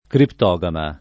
criptogama [ kript 0g ama ]